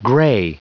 Prononciation du mot gray en anglais (fichier audio)
Prononciation du mot : gray